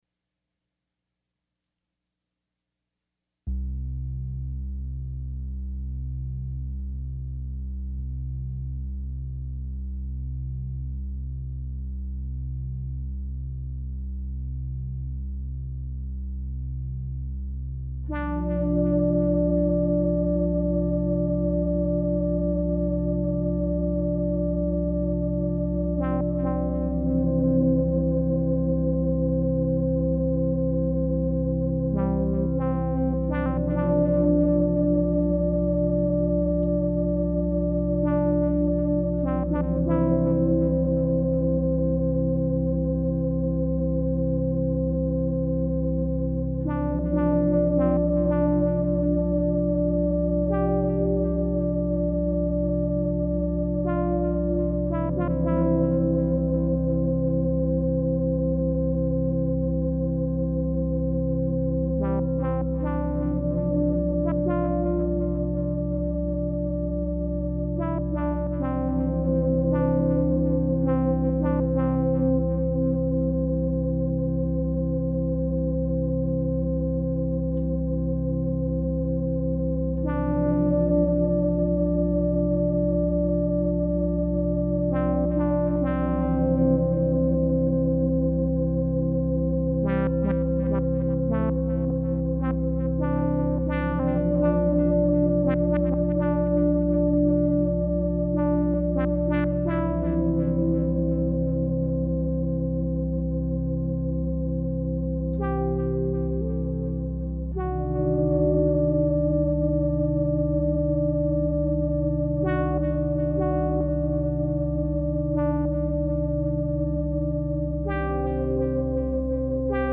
These were improvisations all recorded in late 2005.
Trying to breathe the phrases into a microphone while I played them on the minimoog.
What you’ll hear though is me hitting the microphone stand with my head. And minimoog keys clicking.